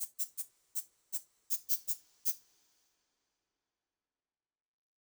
melodic_chardi_arpeggio.wav